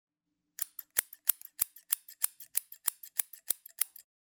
Звуки режущих ножниц
Быстрое чирканье ножницами